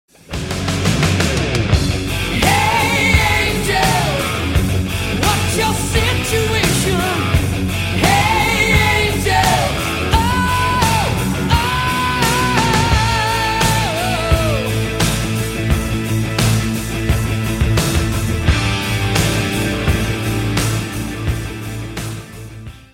• Качество: 192, Stereo
Metal
heavy Metal